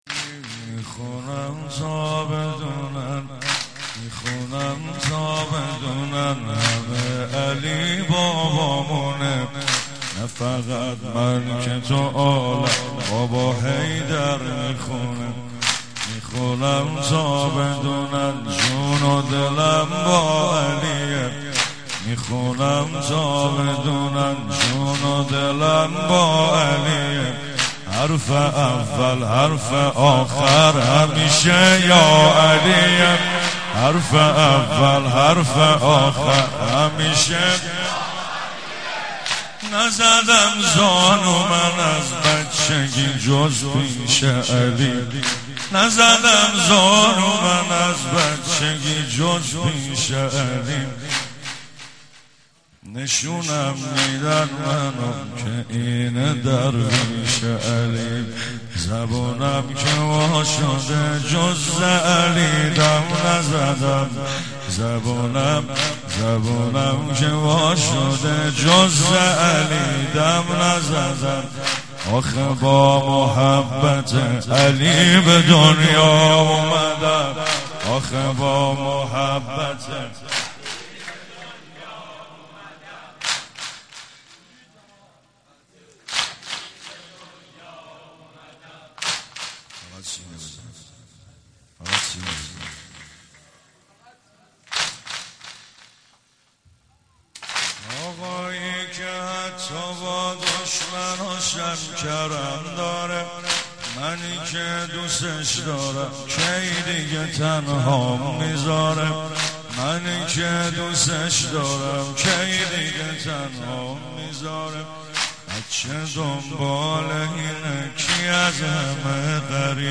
رمضان 89 - سینه زنی 1
رمضان-89---سینه-زنی-1